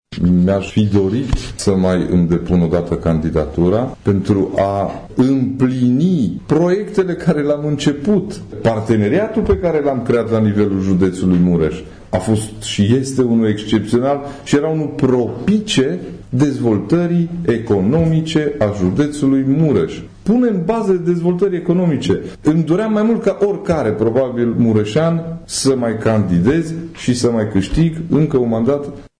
Cei doi au declarat, într-o conferință de presă comună, susținută la sediul PNL din Tîrgu-Mureș, ca eventualele mandate sunt puse la îndoială de noua lege electorală.